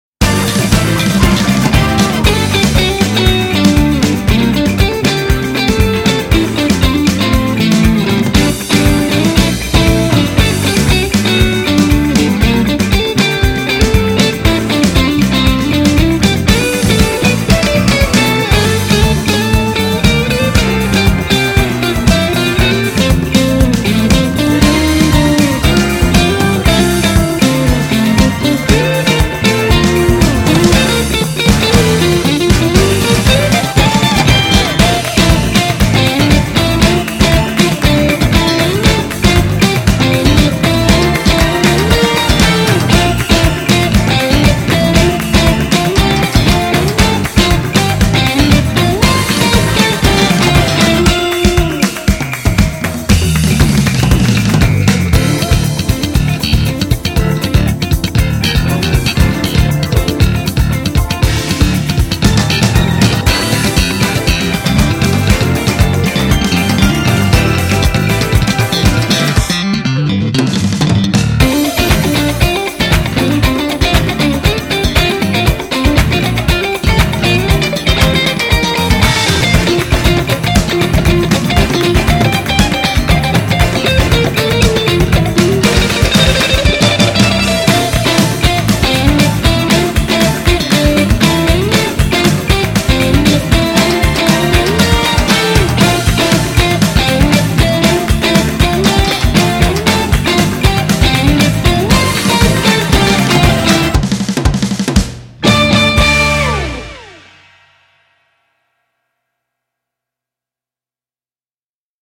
카니발의 분위기를 표현한 퓨젼 곡입니다.